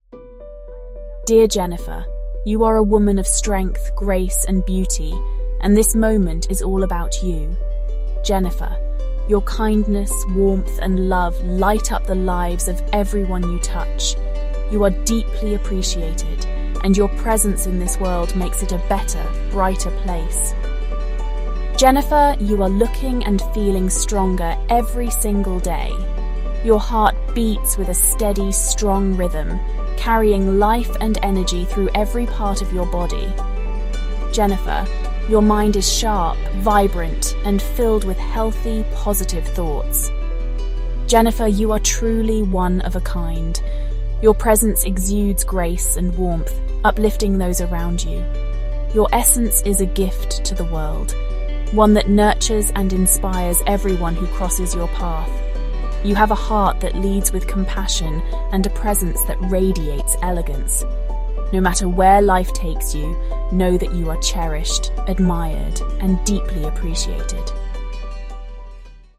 Featuring their name, healing sound frequencies, and layered “YOU ARE” + subliminal “I AM” affirmations to ease anxiety and support memory care.
• Science-backed 528Hz and 40Hz sound frequencies
Repetitive, soothing affirmations combined with carefully selected healing sound frequencies provide a calming experience that can gently redirect agitation, restlessness, or anxiety.
✅ Layered Affirmations: Spoken Comfort & Subliminal Healing